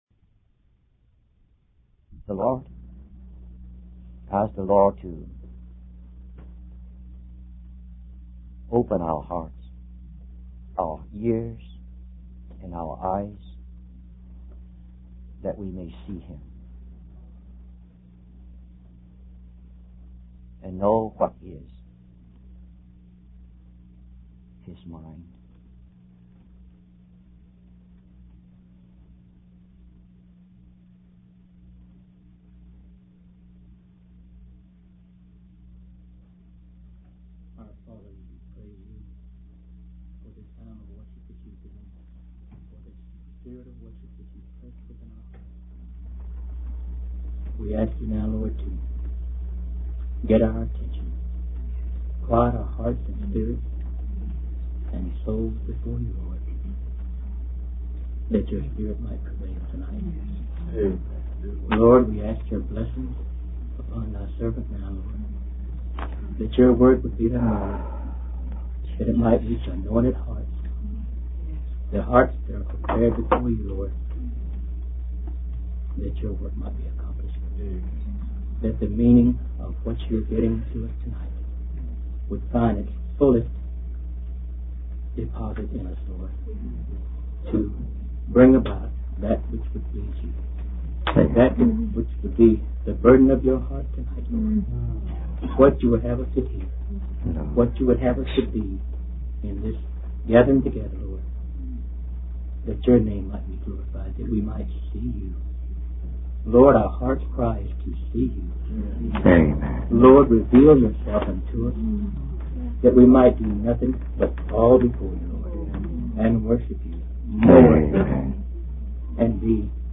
In this sermon, the speaker emphasizes the importance of having a vision among God's people.